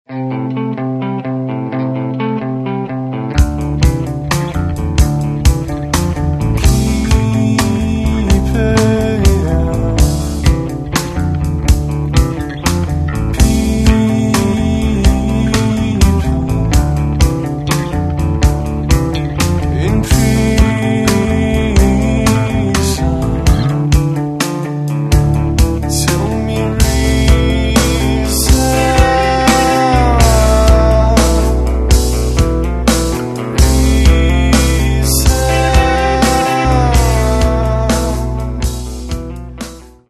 Catalogue -> Rock & Alternative -> Energy Rock
debut disc